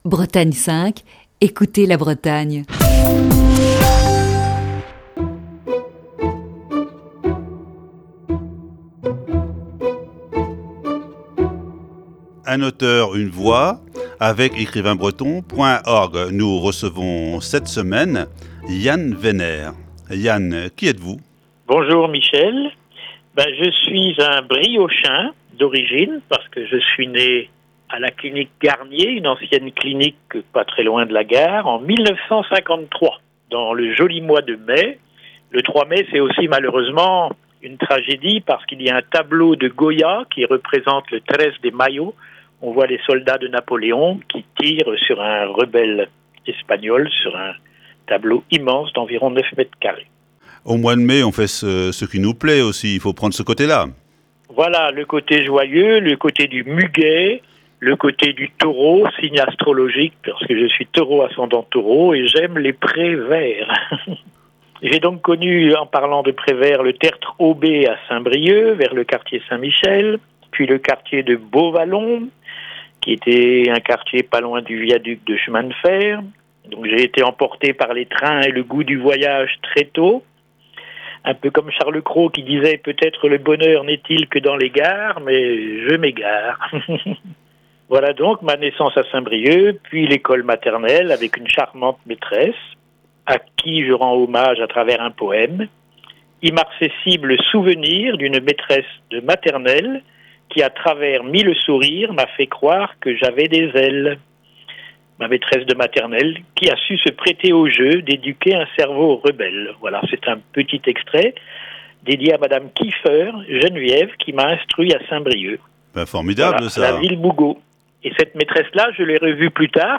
Voici ce lundi, la première partie de cette série d'entretiens.